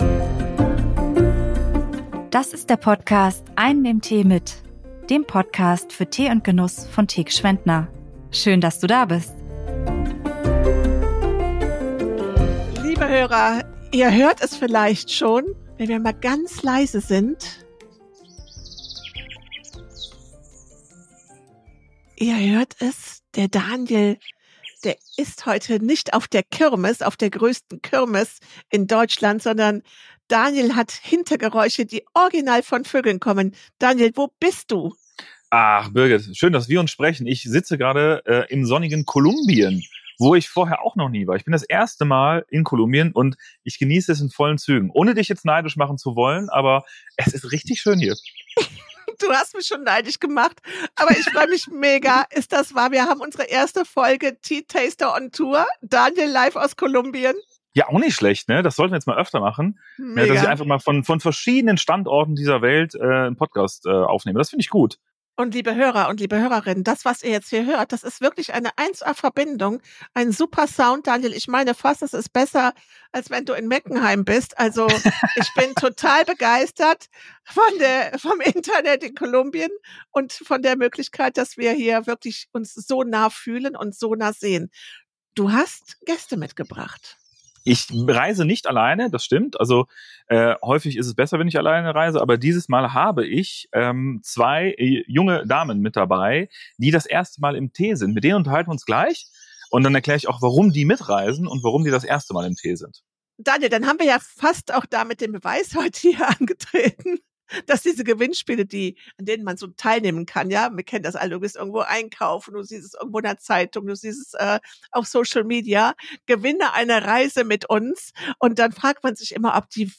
Erstmalig gibt es also eine Podcastfolge direkt aus einem Tee-Ursprungsland!